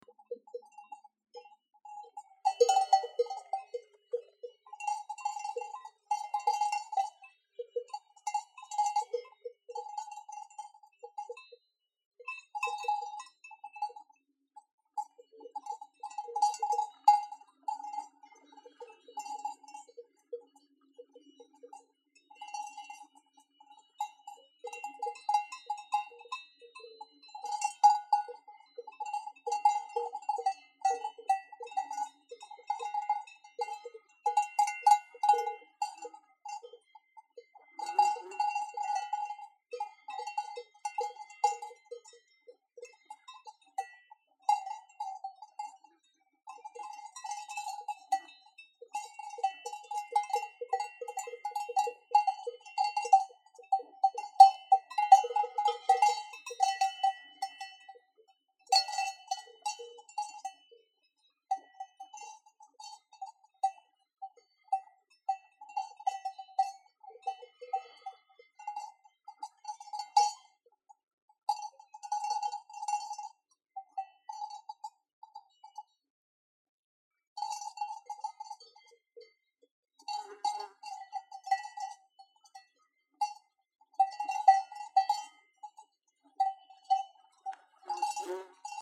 Cows on alpine meadows
Romantic cowbells on summer meadows in South Tyrol
Recording of cows on alpine meadows on July 17. 2016, Speikboden, Ahrnthal, Italy
Captured with iPhone 6 and Rode iXY.